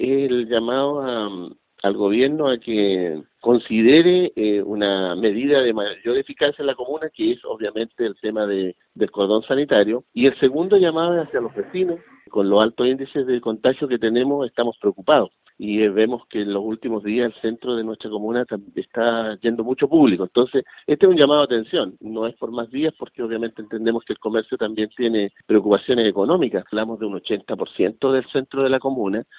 Así lo señaló uno de los integrantes de la instancia, el concejal Francisco Reyes (PPD), quien agregó que cerca del 80% de los locales, asociados a la Cámara de Comercio, cerrará sus puertas.